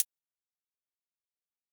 Sicko Hi-Hat - Hi Pitch(1).wav